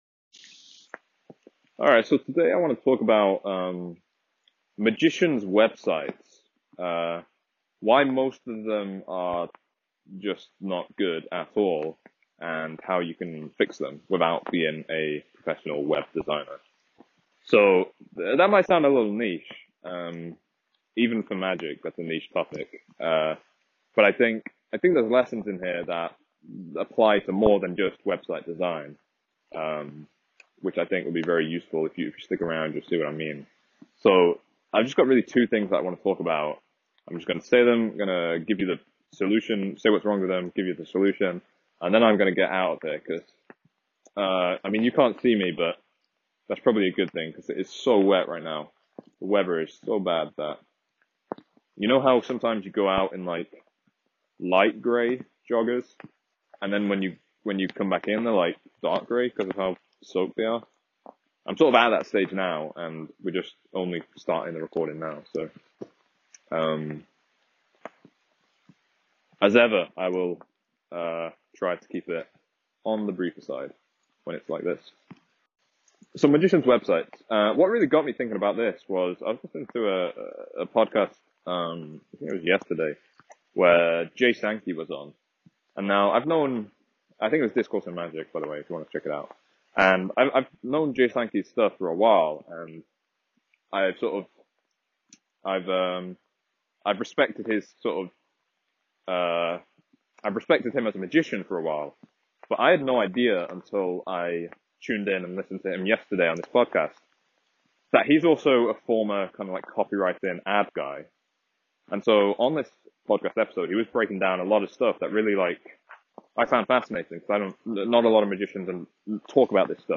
DISCLAIMER: he had this idea while on a walk one day, so it is all recorded on his iPhone with the lovely British rain in the background.